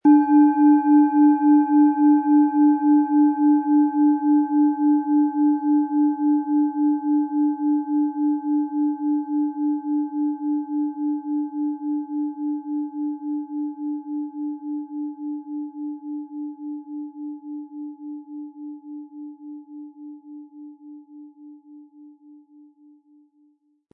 Planetenton 1
Planetenschale® Verantwortlich sein können & Innerlich ruhig und gesammelt fühlen mit Saturn, Ø 17,9 cm inkl. Klöppel
SchalenformBihar
HerstellungIn Handarbeit getrieben
MaterialBronze